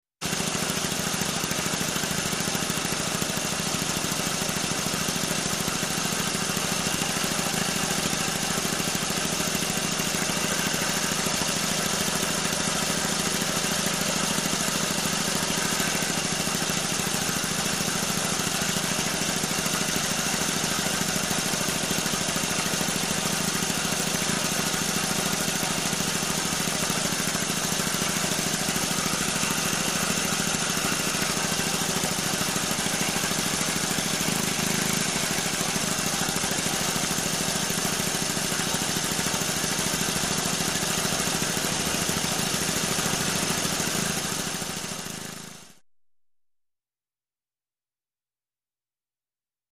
Lawnmower
Lawnmower Engine, 3 1 / 2 Horsepower With Low R.P.M., Medium Perspective, Steady, With Rotating Blades.